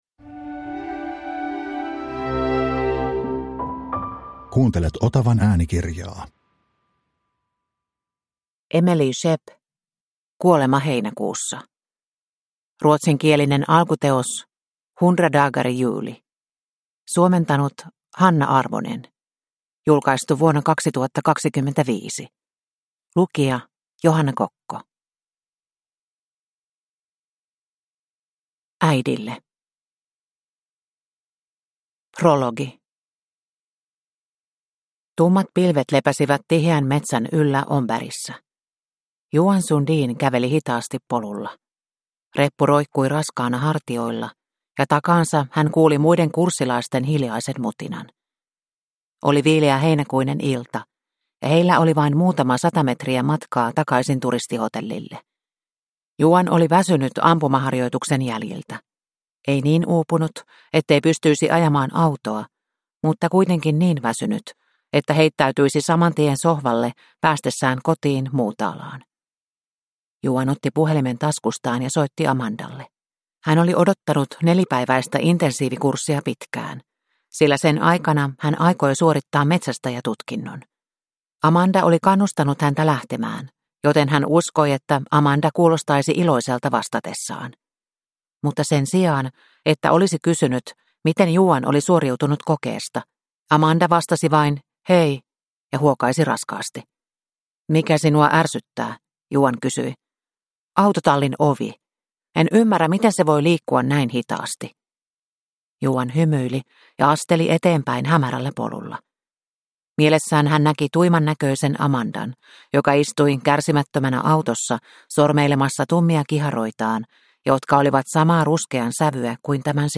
Kuolema heinäkuussa (ljudbok) av Emelie Schepp